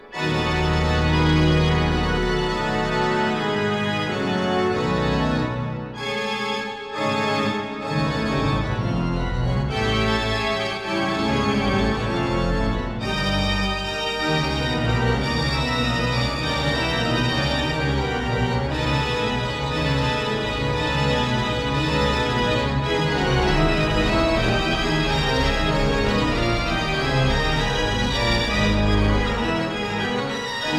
Stereo recording made in Dvořák Hall, Prague 22- 24 May 1961